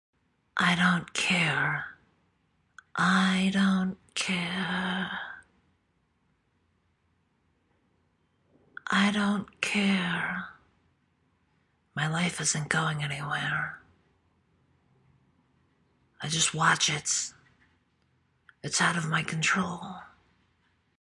女子叹气
描述：一个女人叹了口气。听起来她过得很艰难。
标签： 激怒 烦躁 沮丧 声音 女孩 人类 失望 悲伤 叹息 疲惫 OWI 女人 身体 声音
声道立体声